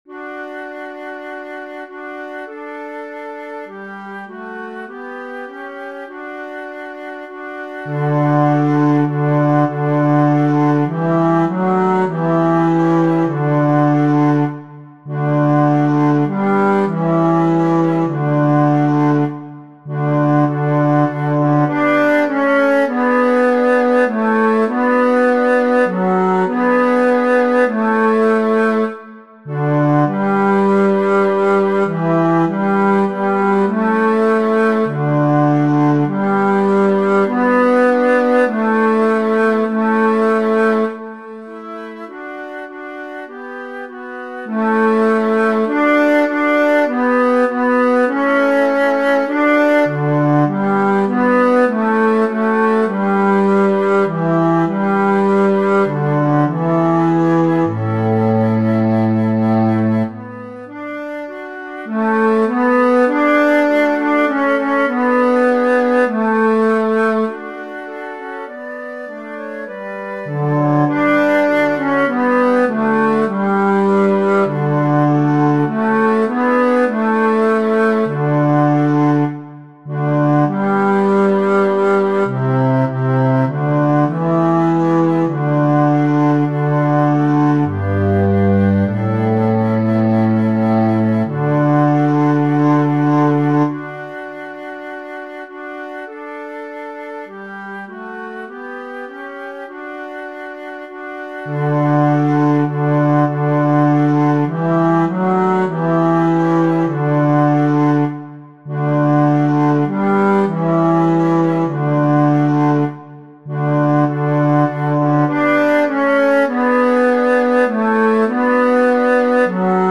Para aprender la melodía podéis utilizar estos enlaces instrumentales en formato MP3:
Pan divino B MIDIDescarga
pan-divino-b-midi.mp3